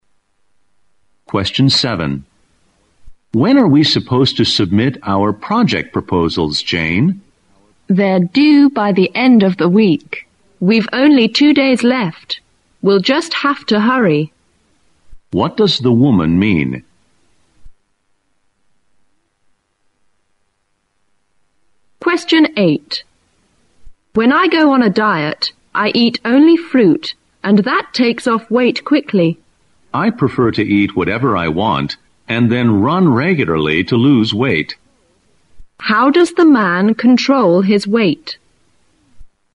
新编六级听力短对话每日2题 第116期